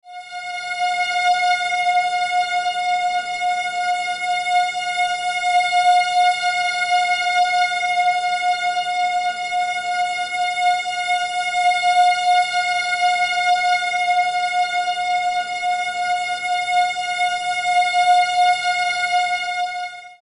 Music tones for String Animation Below
35_High_F.mp3